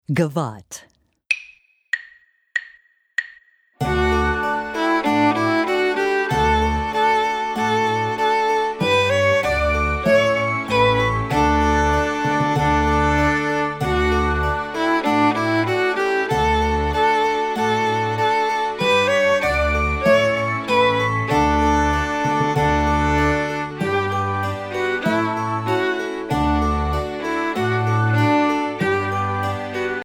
Voicing: Viola w/mp